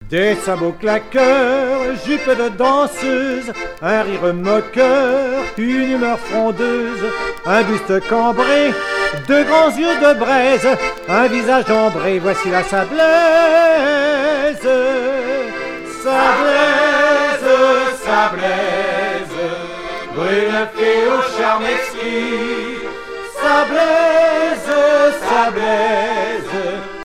danse : valse
Groupe folklorique des Sables-d'Olonne
Pièce musicale éditée